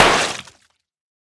Eyes-HeadPop.ogg